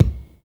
X KICK 2.wav